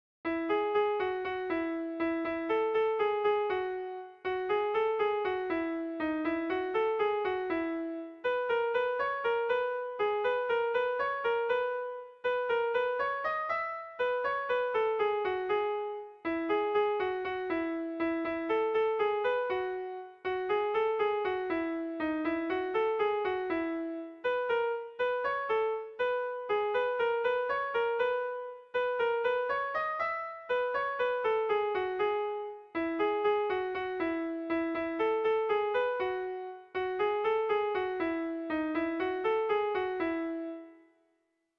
Bertso melodies - View details   To know more about this section
Irrizkoa
ABDEAB